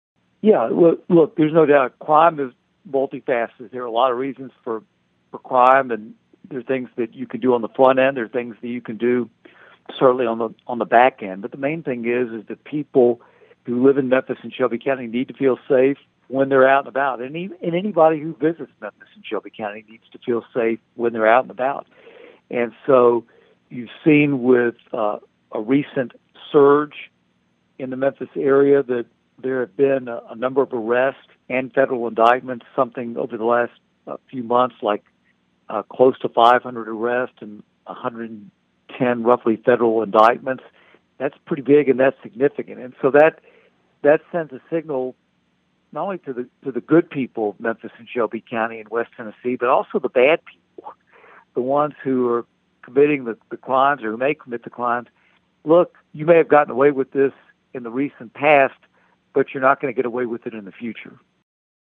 Congress Kustoff told Thunderbolt News the crime situation could be corrected.(AUDIO)